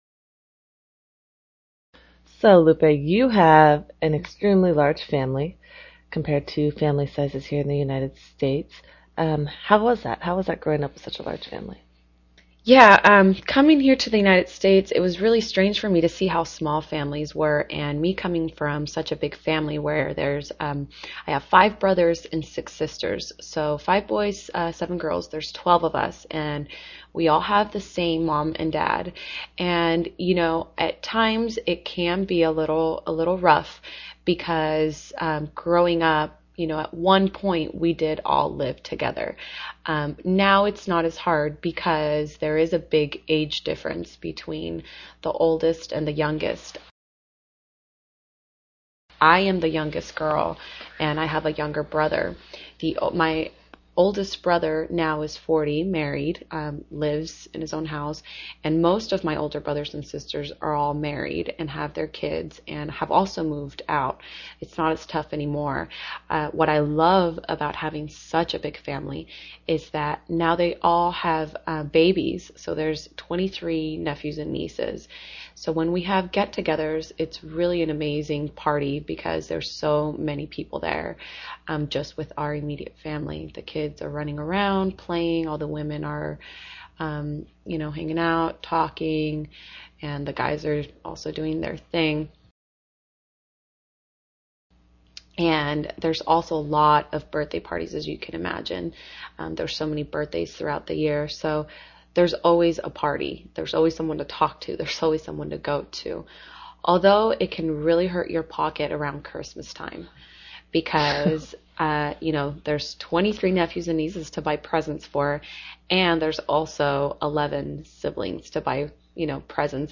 You will listen to an interview between two women.